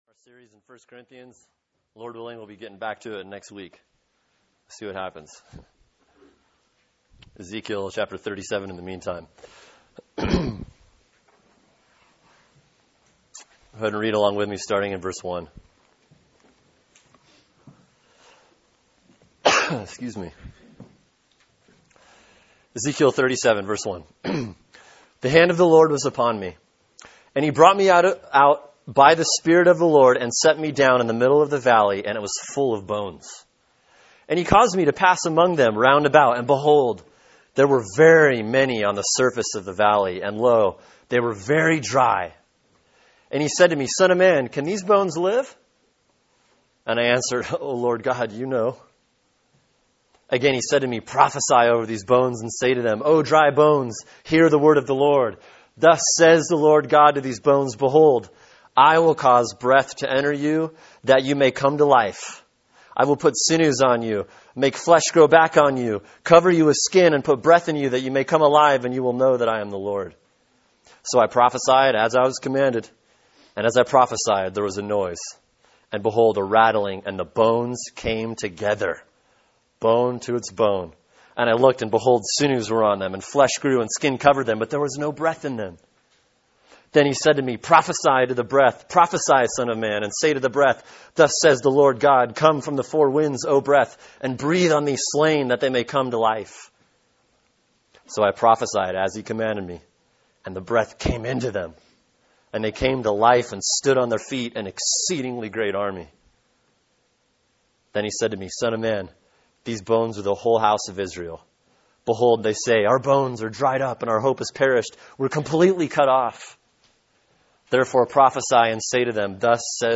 Sermon: Ezekiel 37:1-14 “The Divine Initiative” | Cornerstone Church - Jackson Hole